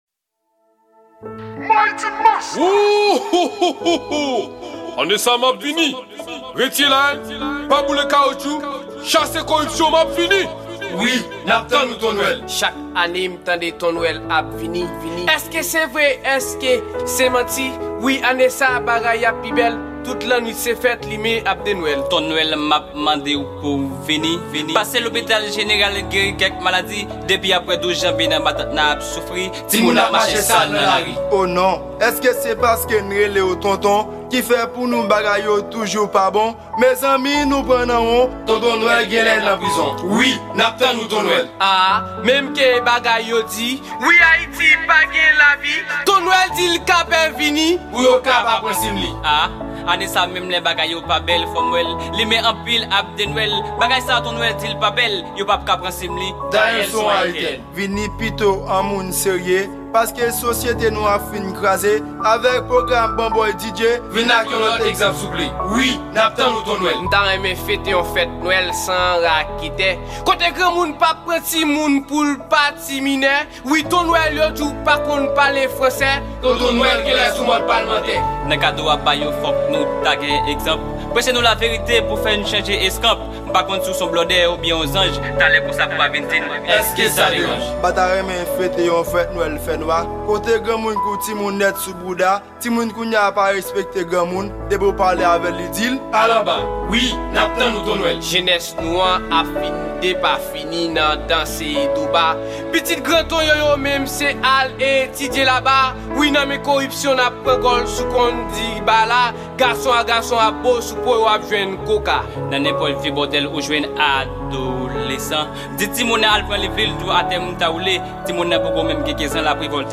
Genre : SLAM